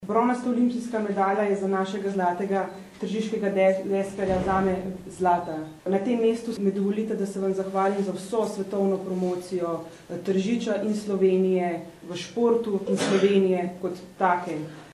izjava_obcinskasvetnicainposlankavdzrsandrejapotocnik.mp3 (365kB)